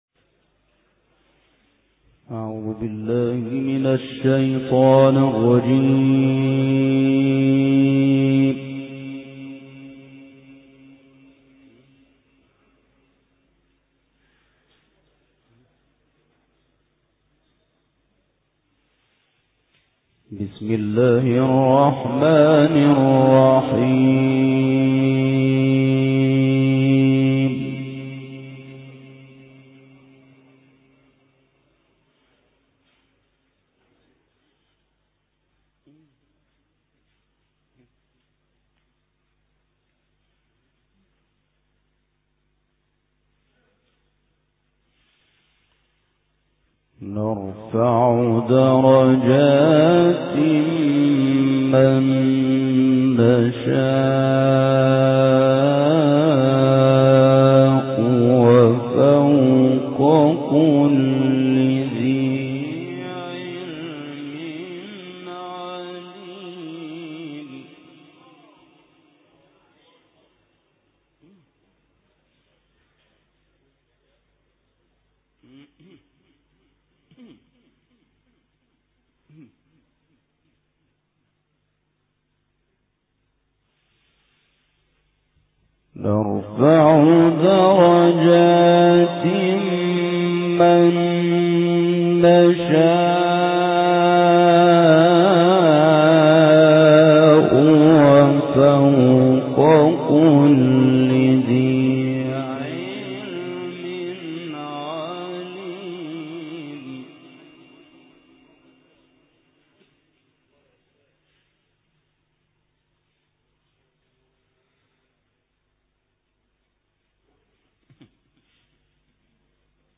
May God make us meet your good expectations - El Forqaan For Recitations and Quran Science